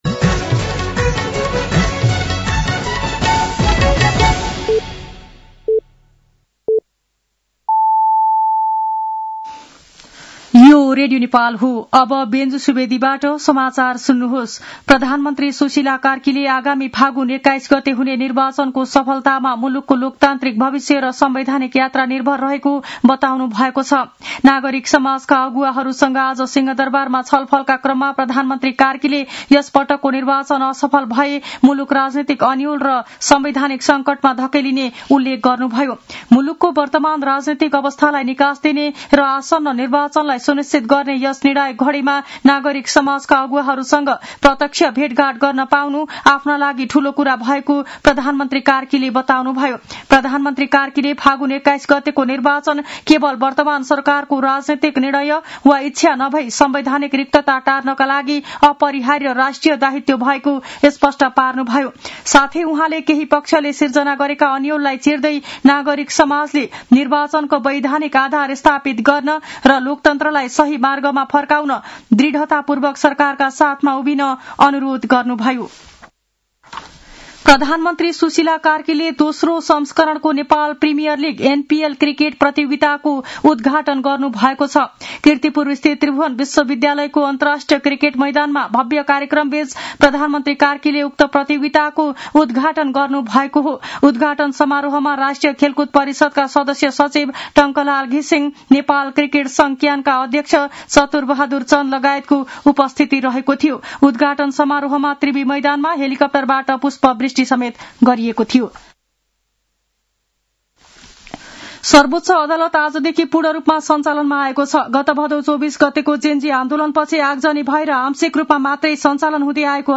साँझ ५ बजेको नेपाली समाचार : १ मंसिर , २०८२
5.-pm-nepali-news-1-2.mp3